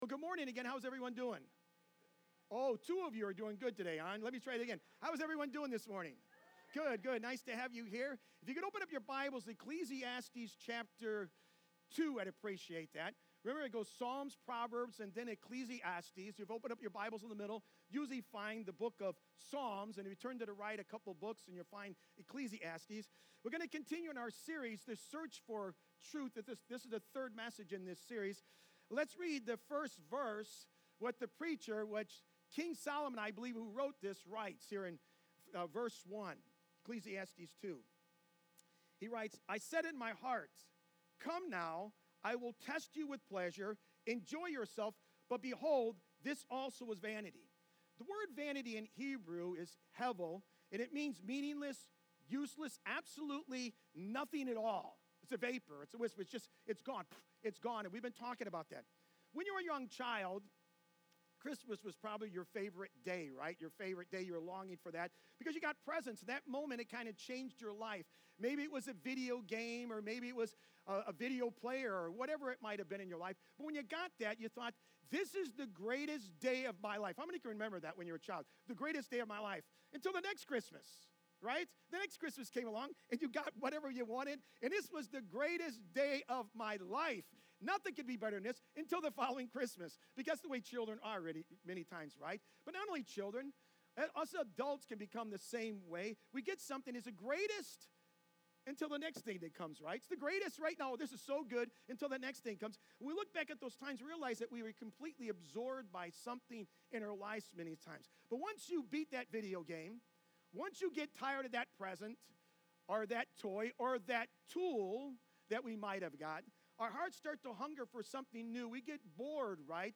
8-17-25-sermon.mp3